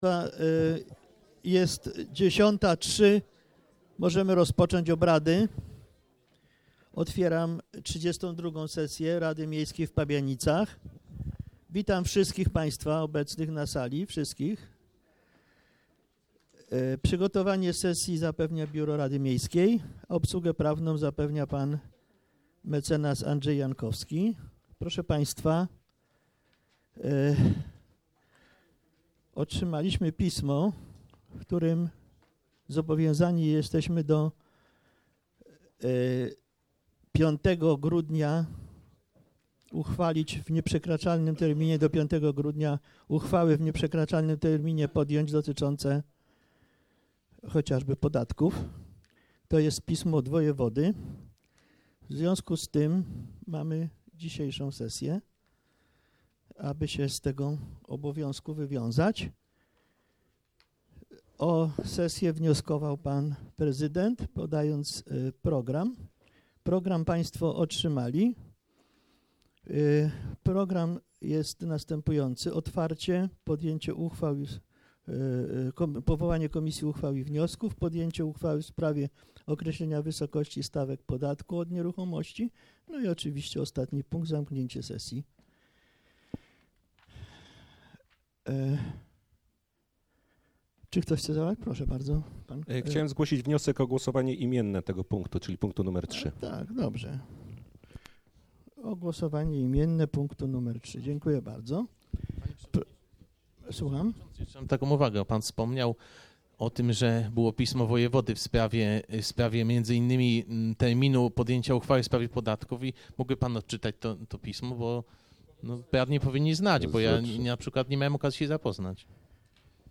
XXXII sesja Rady Miejskiej w Pabianicach - 17 listopada 2016 r. - 2016 rok - Biuletyn Informacji Publicznej Urzędu Miejskiego w Pabianicach